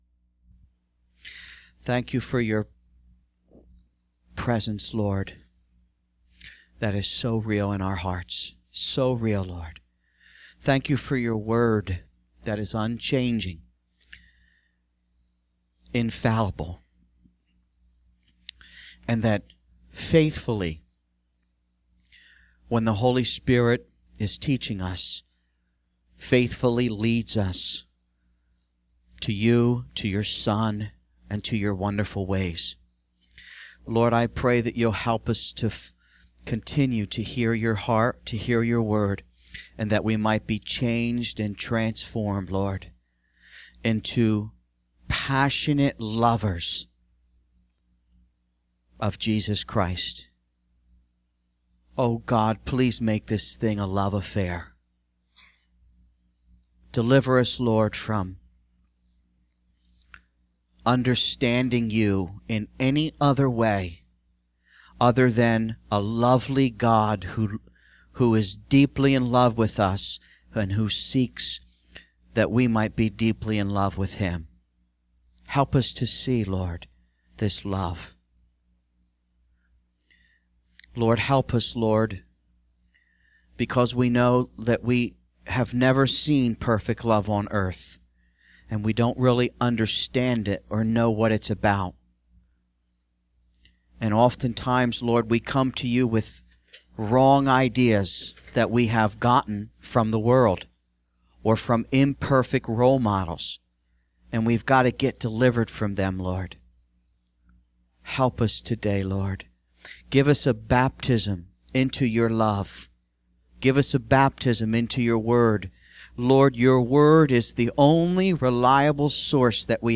The sermon also discusses the significance of family as a reflection of God's love and the dangers of a dysfunctional family structure, which can hinder our understanding of divine love.